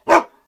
bark2.ogg